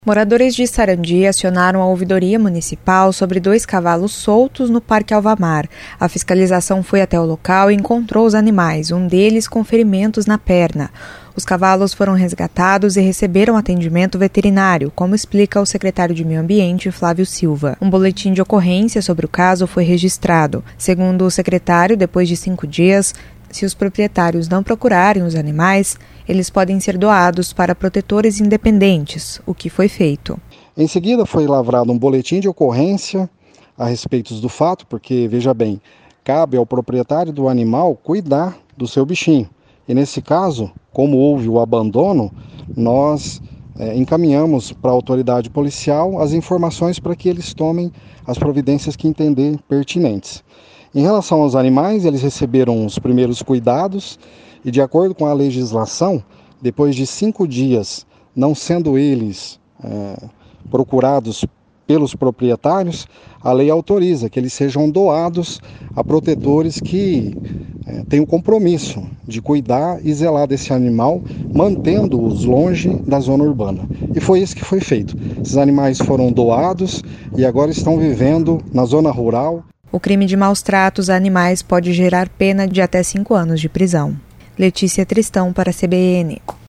Os cavalos foram resgatados e receberam atendimento veterinário, como explica o secretário de Meio Ambiente de Sarandi, Flávio Silva.